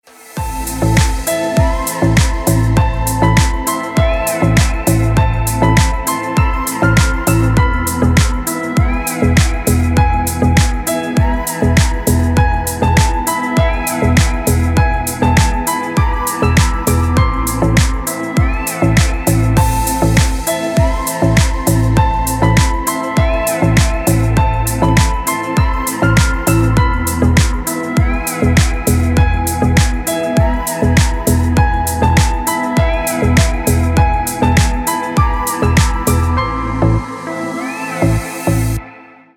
Душевная мелодия